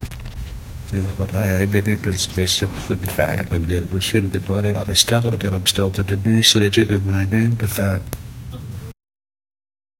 Royalty-free conversation sound effects
men catching up with friends.
men-catching-up-with-frie-b6p6nscq.wav